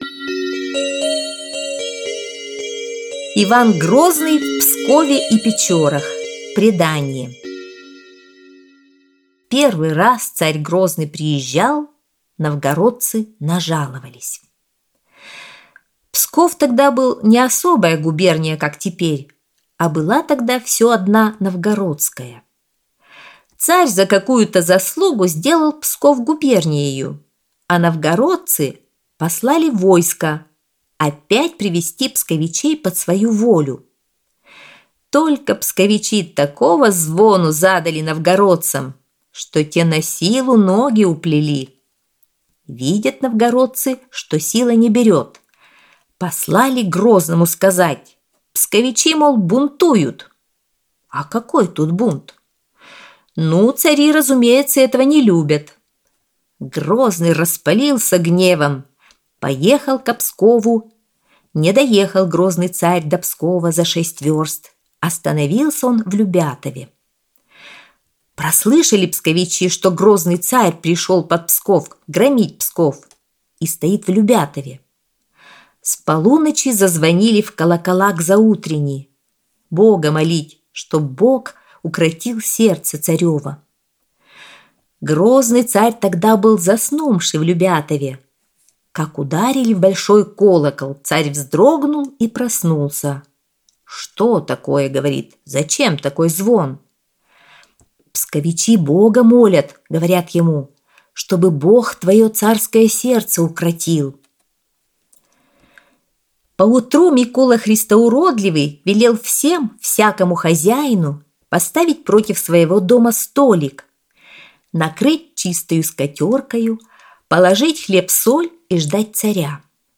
Аудиосказка «Иван Грозный в Пскове и Печорах»